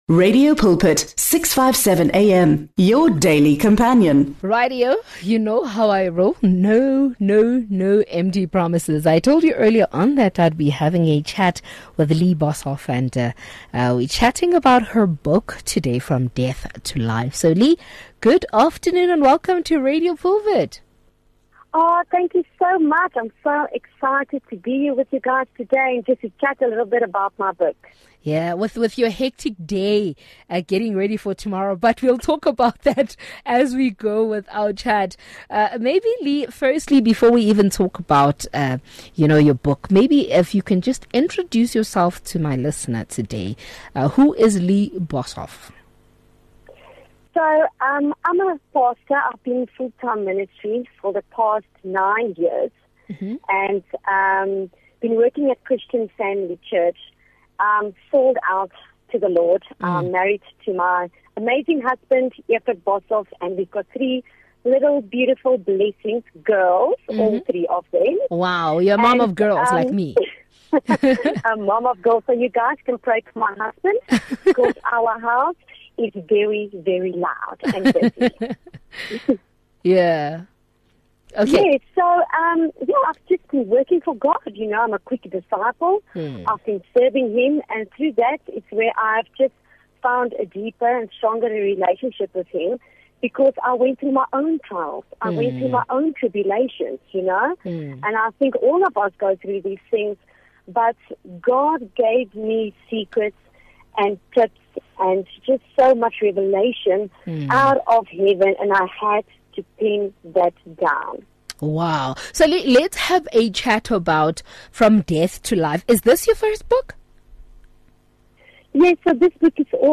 2 Sep Book Review